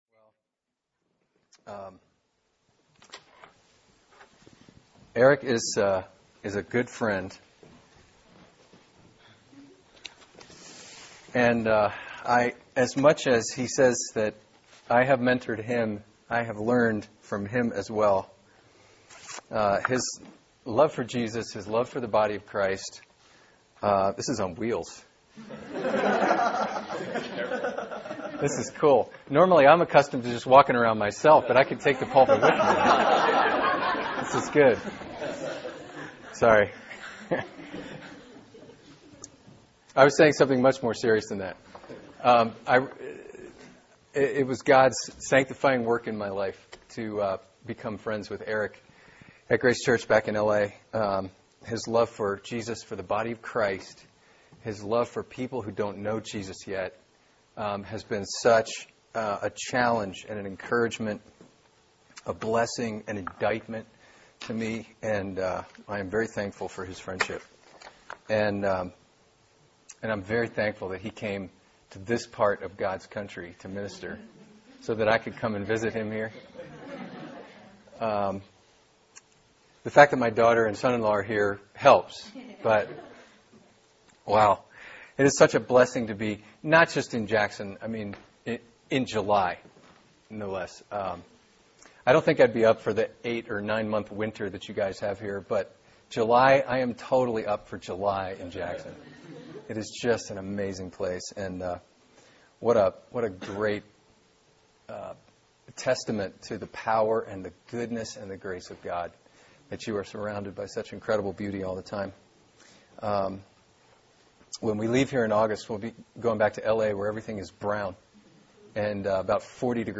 Sermon: various passages “What Is Worship?”